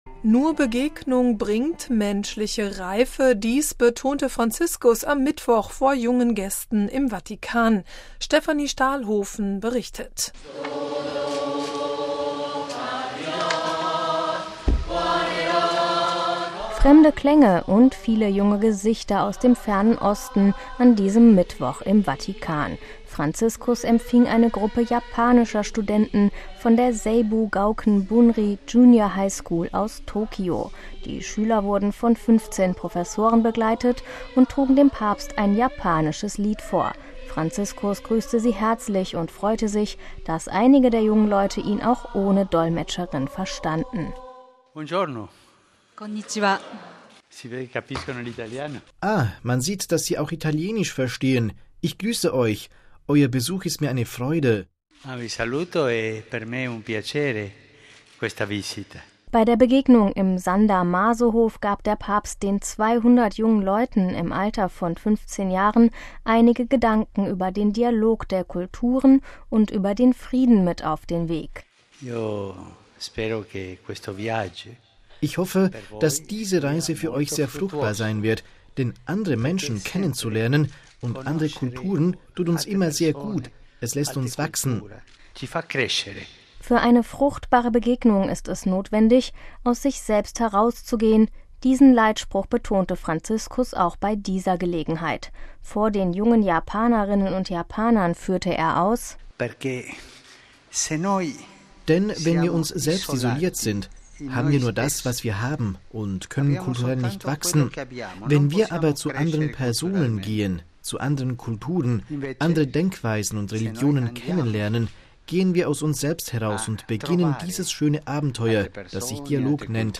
MP3 Fremde Klänge und viele junge Gesichter aus dem fernen Osten an diesem Mittwoch im Vatikan: Franziskus empfing eine Gruppe japanischer Studenten von der Seibu Gauken Bunri Junior High School aus Tokyo. Die Schüler wurden von 15 Professoren begleitet und trugen dem Papst ein japanisches Lied vor.
Bei der Begegnung im San Damaso-Hof gab der Papst den 200 jungen Leuten im Alter von 15 Jahren einige Gedanken über den Dialog der Kulturen und den Frieden mit auf den Weg.